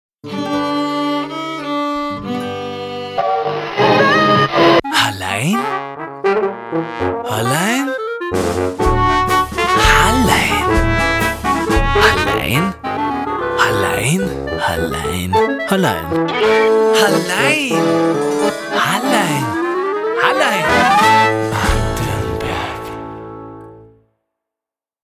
untermalt von verschiedenen Instrumenten.